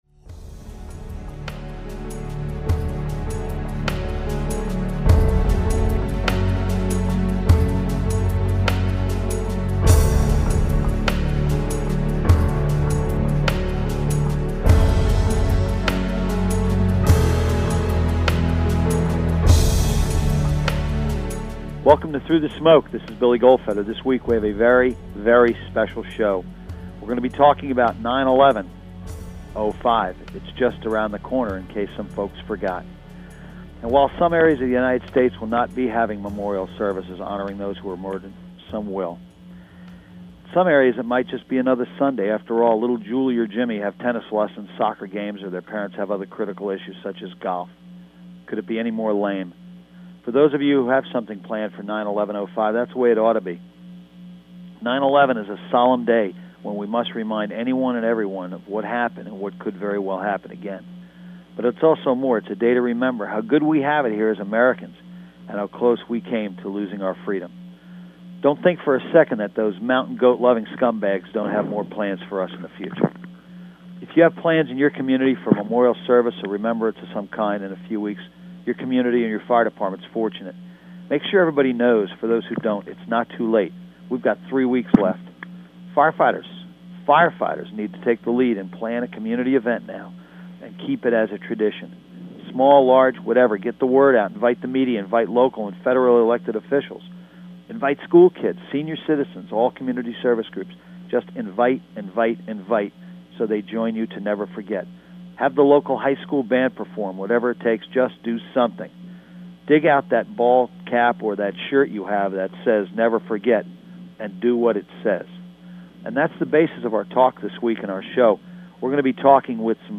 an in-depth and deeply personal discussion with three people who share a very emotional bond